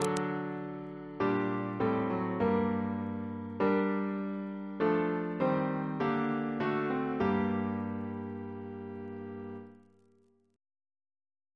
Single chant in G minor Composer: Henry Purcell (1659-1695) Reference psalters: ACP: 123; PP/SNCB: 169